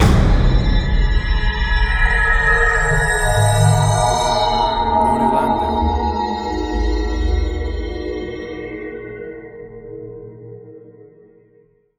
BIG HIT WITH LASER SYNTH – 0:12″
A dark and scary Horror Hit! Perfect for Horror Trailers for hitting your cue points and making a big scary impact!
WAV Sample Rate: 16-Bit stereo, 44.1 kHz
Big-Hit-With-Laser-SynthCS.mp3